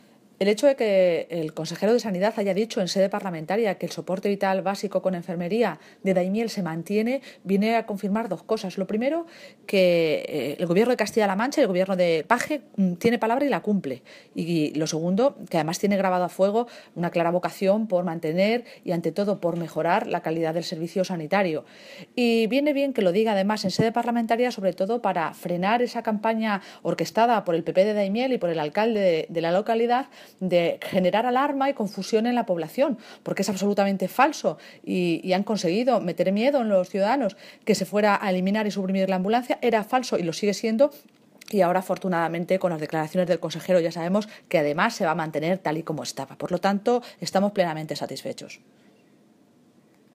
La Vicesecretaria General y Portavoz del PSOE de Castilla-La Mancha, Cristina Maestre, se ha pronunciado desde Toledo sobre el anuncio del Consejero de Sanidad en torno a la permanencia en Daimiel del Soporte Vital Básico con enfermería, tal y como se venía prestando.
Cortes de audio de la rueda de prensa